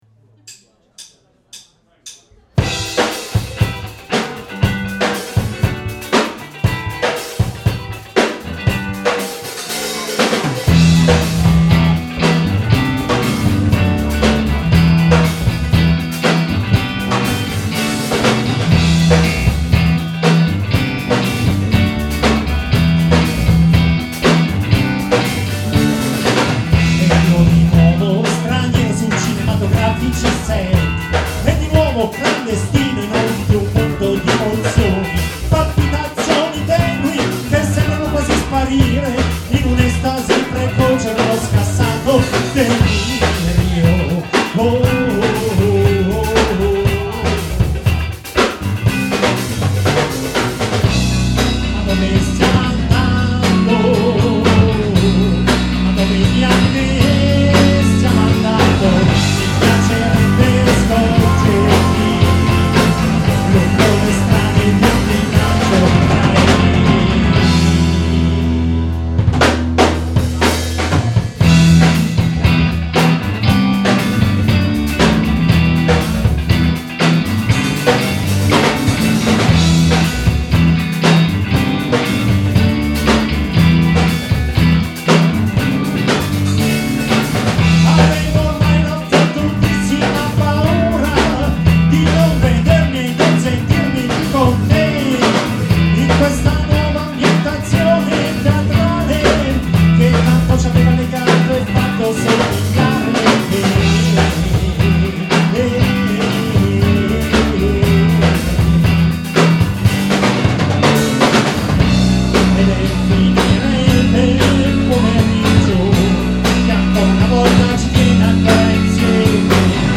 with ORTF stereo configuration,